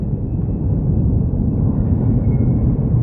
rumbling.wav